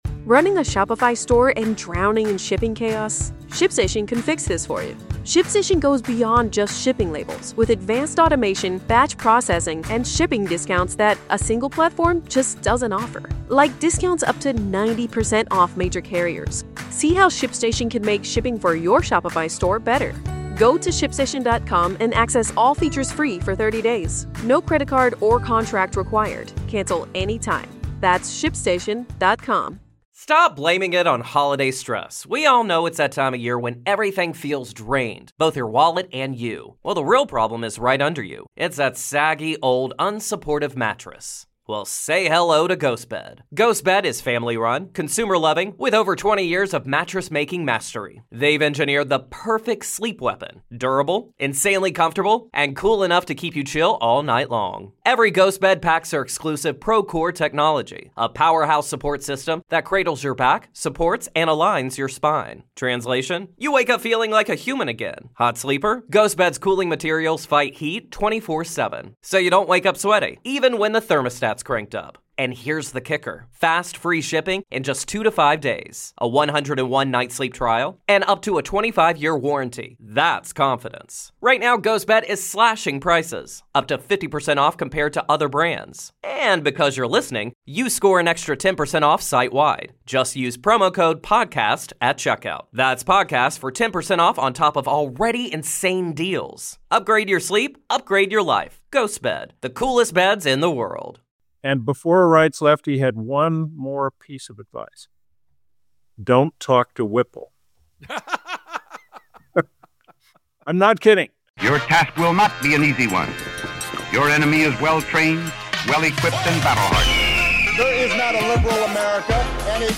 Giving you an extra special bonus episode of The Lincoln Project podcast, Rick Wilson is joined by special guest Chris Whipple of Vanity Fair, the reporter who just detonated Trumpworld with his explosive reporting on Susie Wiles.